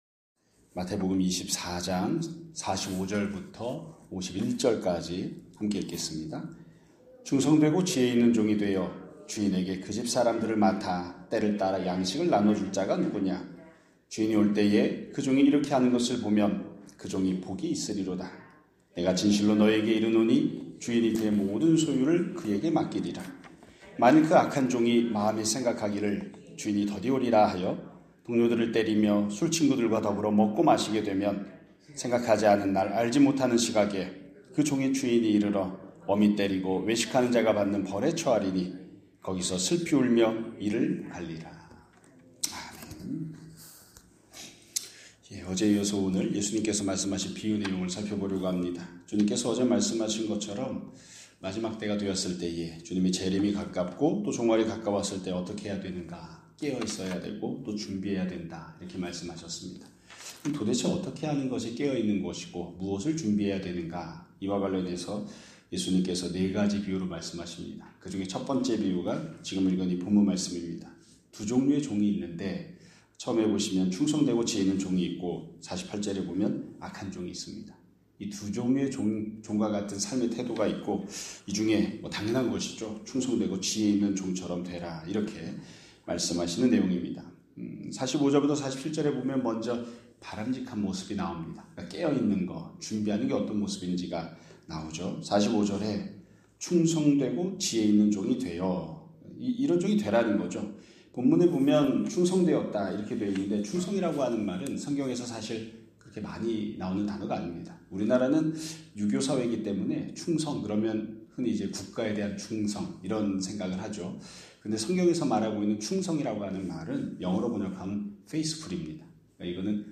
2026년 3월 17일 (화요일) <아침예배> 설교입니다.